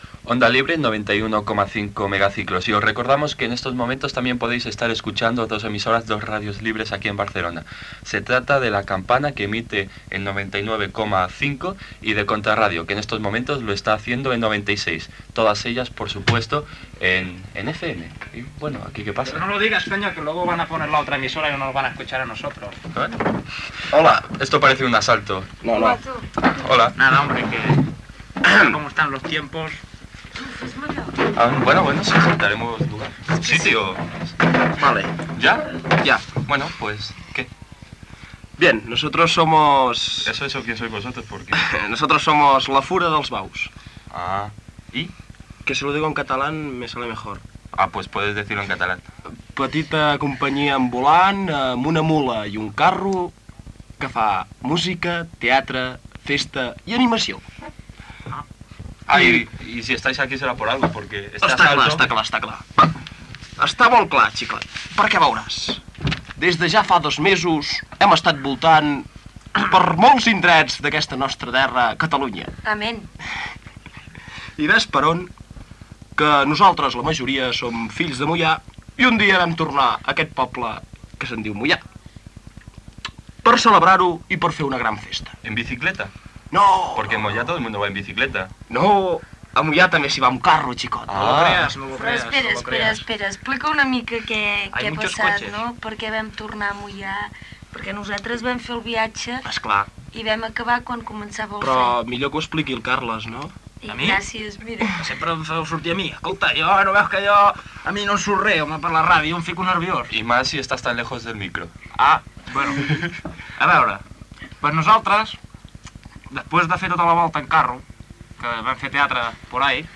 44103ed5fd9c96879d448ce58ee46ca722423bcb.mp3 Títol Ona Lliure Emissora Ona Lliure Titularitat Tercer sector Tercer sector Lliure Descripció Identificació, freqüències de La Campana i Contrarradio, Entrevista a La Fura dels Baus (Carles Padrissa).